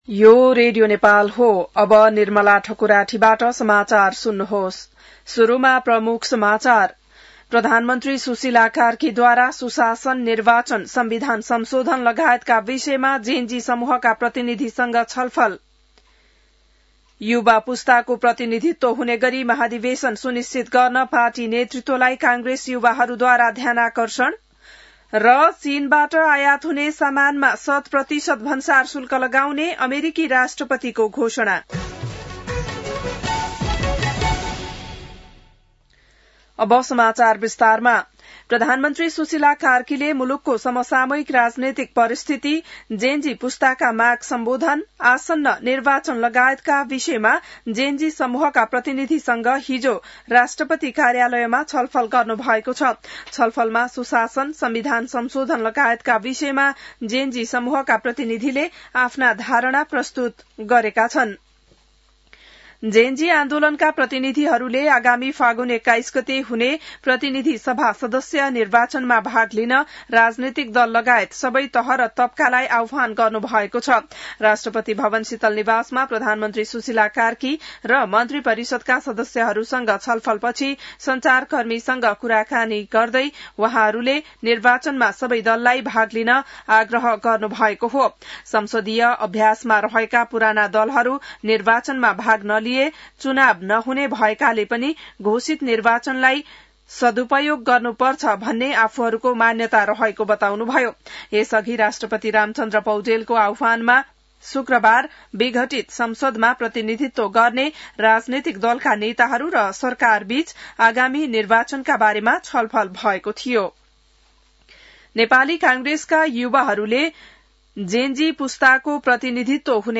बिहान ९ बजेको नेपाली समाचार : २६ असोज , २०८२